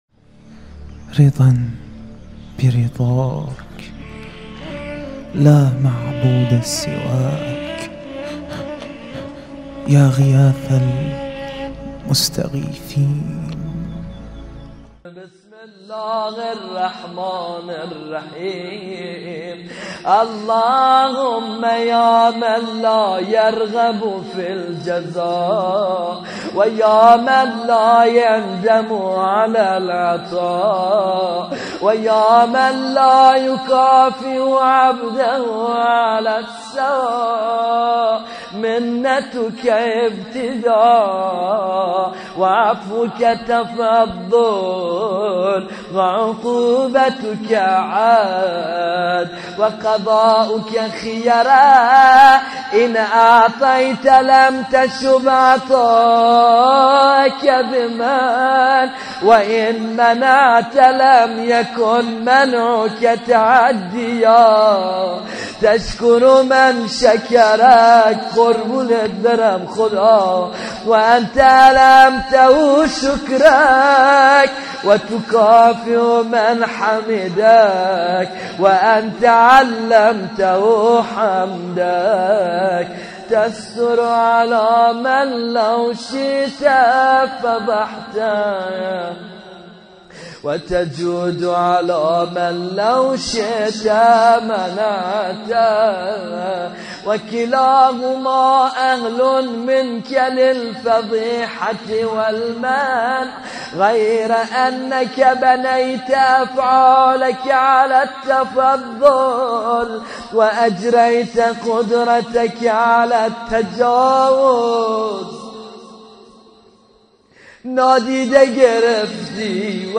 قرائت دعای وداع با ماه رمضان
هیأت علی اکبر بحرین